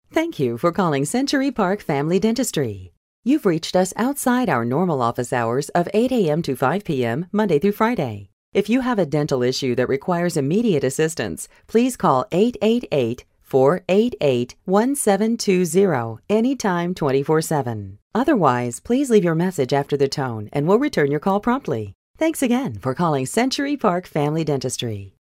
Customized Voicemail recordings.
Voicemail